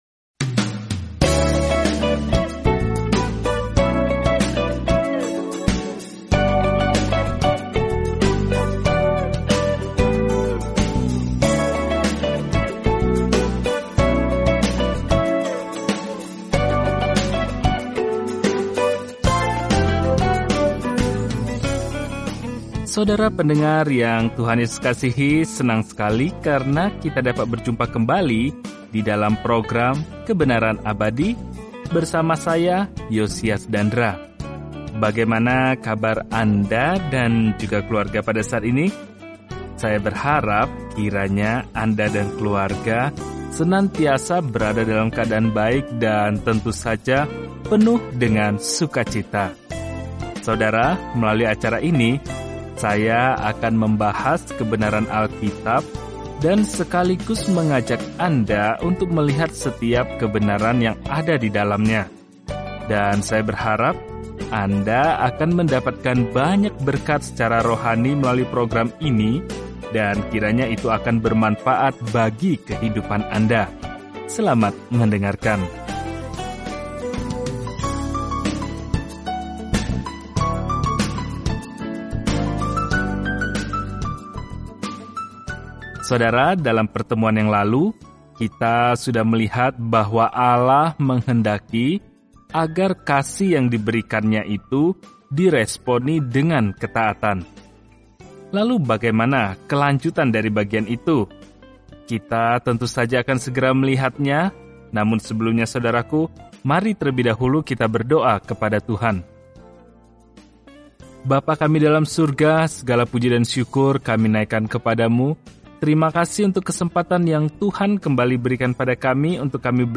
Firman Tuhan, Alkitab Ulangan 11:15-32 Ulangan 12 Ulangan 13:1-5 Hari 7 Mulai Rencana ini Hari 9 Tentang Rencana ini Ulangan merangkum hukum baik Allah dan mengajarkan bahwa ketaatan adalah tanggapan kita terhadap kasih-Nya. Telusuri Ulangan setiap hari sambil mendengarkan pelajaran audio dan membaca ayat-ayat tertentu dari firman Tuhan.